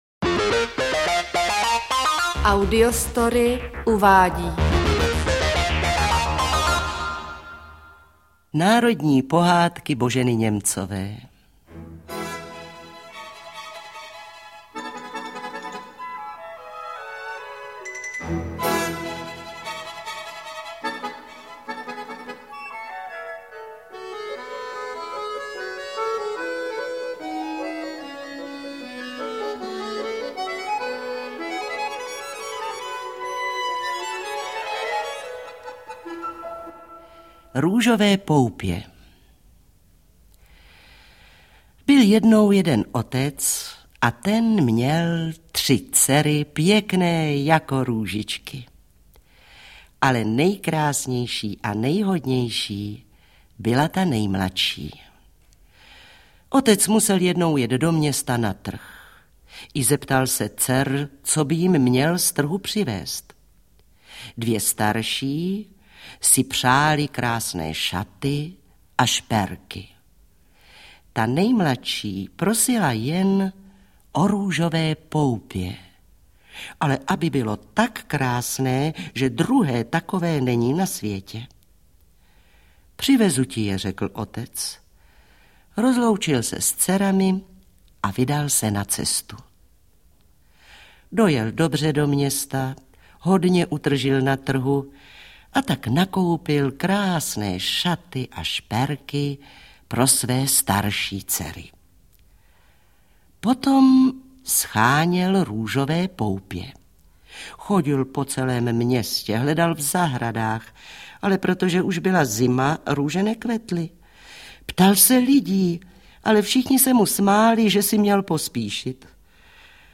Interpret:  Antonie Hegerlíková